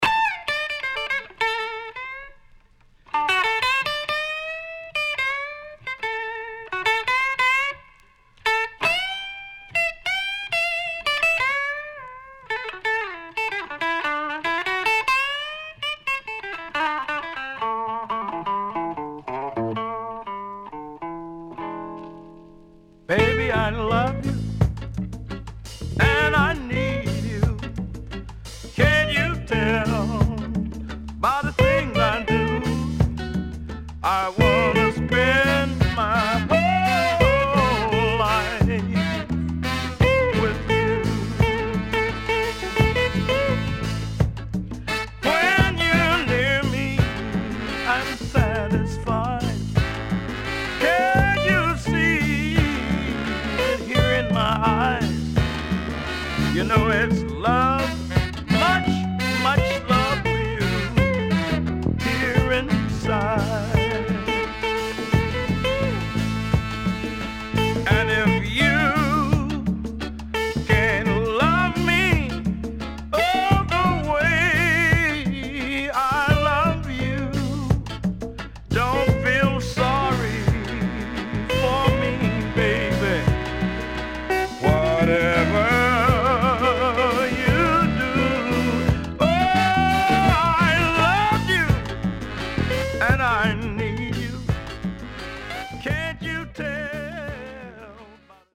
HOME > SOUL / OTHERS
SIDE A:少しチリノイズ入りますが良好です。